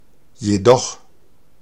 Ääntäminen
IPA : /jɛt/